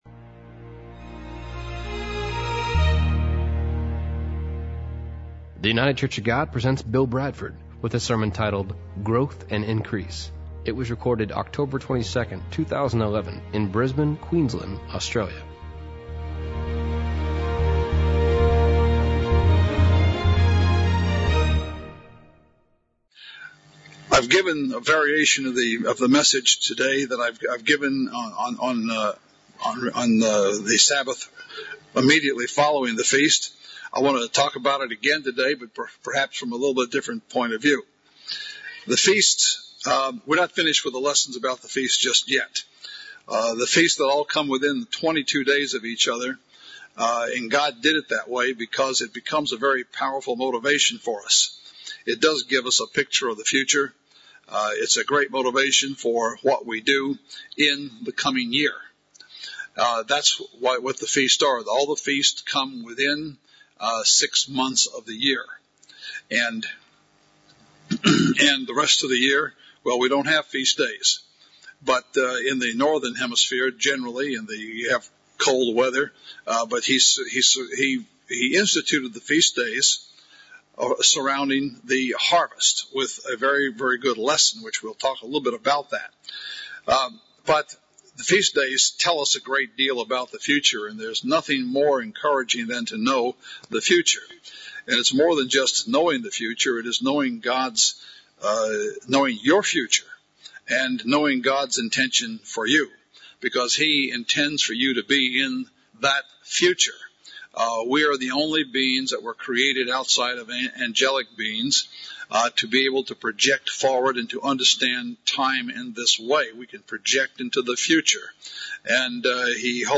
Sermons
Given in Brisbane